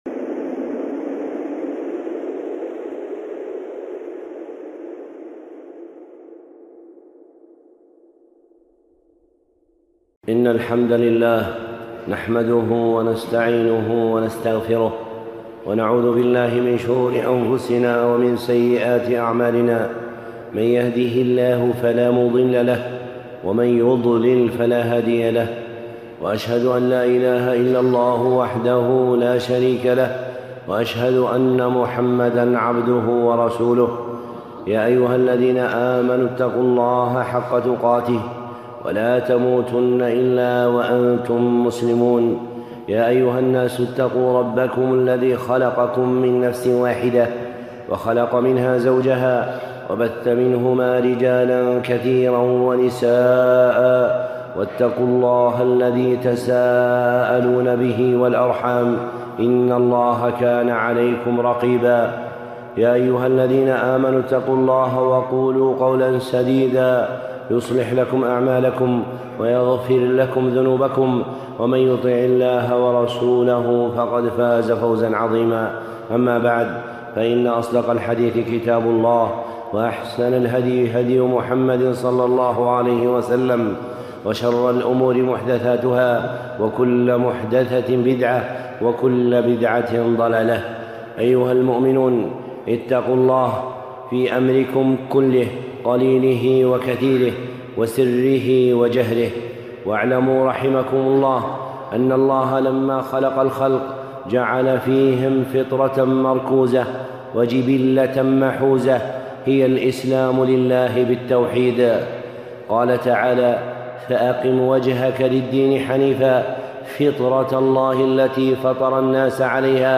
خطبة (فطرة الله) الشيخ صالح العصيمي